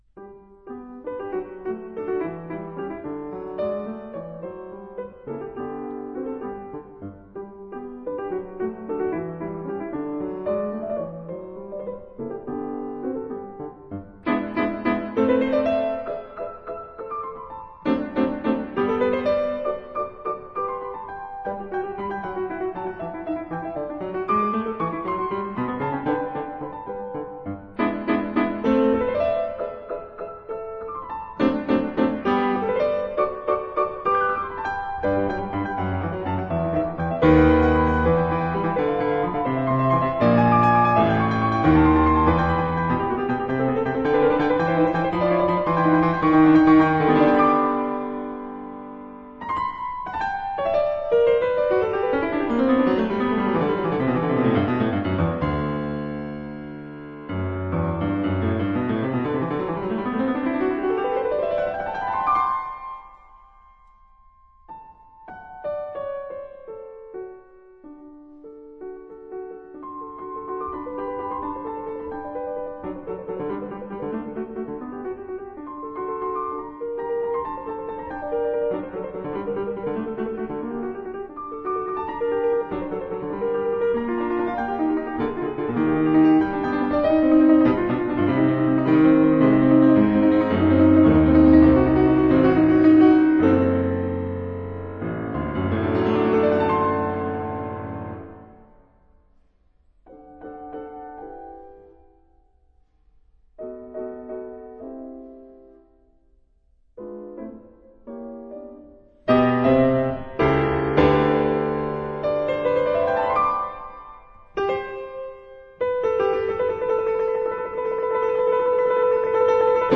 那歌唱像是不斷地水流，把結構牢牢地環繞著。
他對每顆音的處裡與交待，完全不模糊。